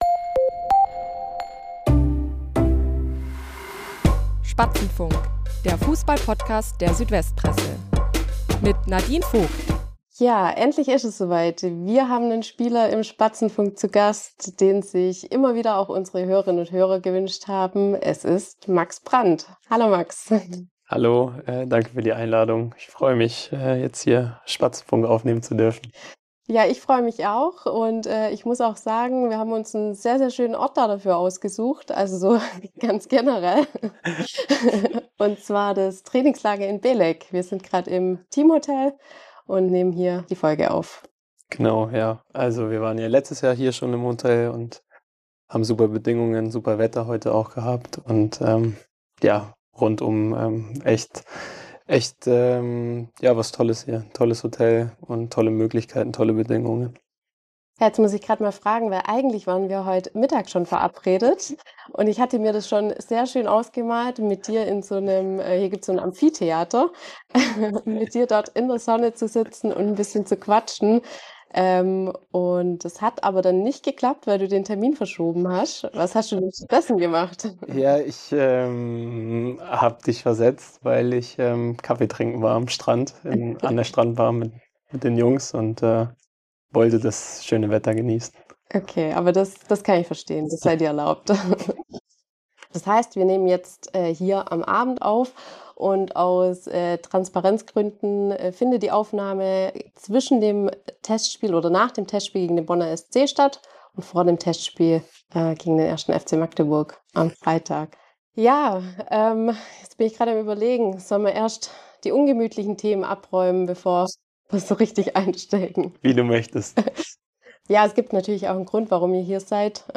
Der Spatzenfunk meldet sich aus dem Türkei-Trainingslager - und das mit einem besonderen Gast.
Das Gespräch: ernst, lustig, kurzweilig.